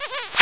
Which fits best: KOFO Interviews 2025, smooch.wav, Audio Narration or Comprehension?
smooch.wav